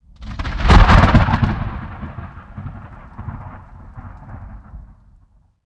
thunder29.ogg